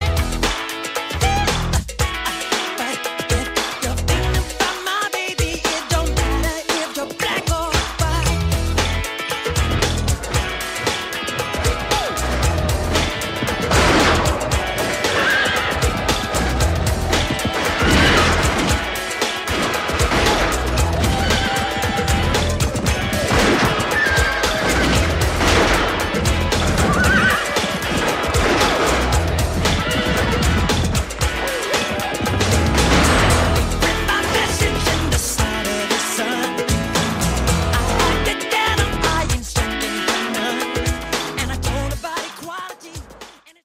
Tonos de canciones del POP